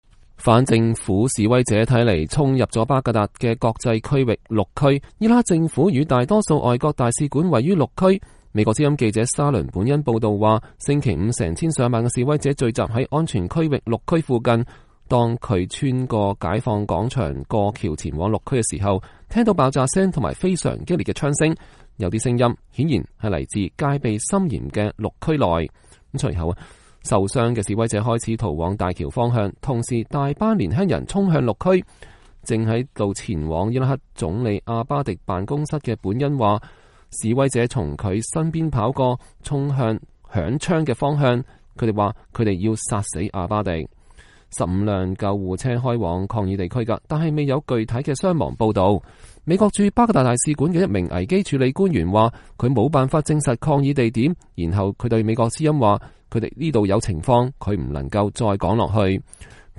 巴格達示威者衝向綠區 激烈槍聲響起